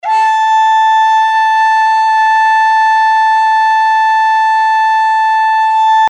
interactive-fretboard / samples / saxophone / A5.mp3